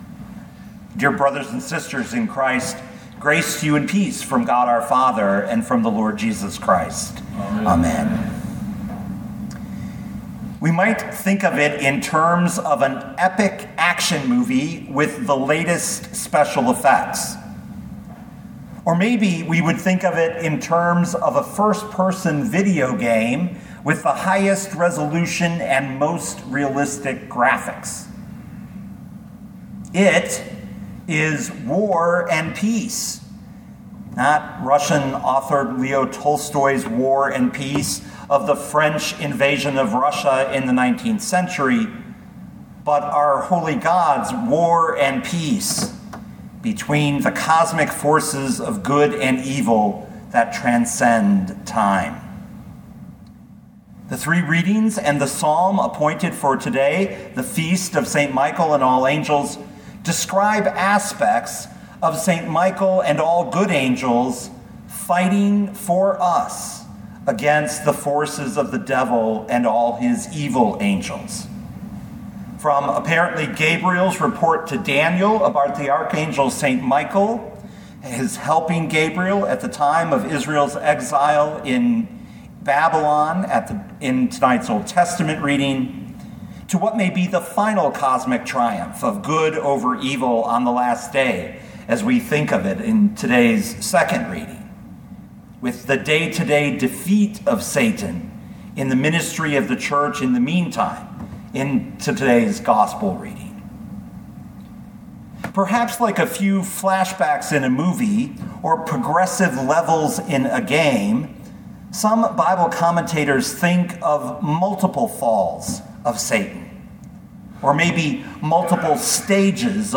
2022 Luke 10:17-20 Listen to the sermon with the player below, or, download the audio.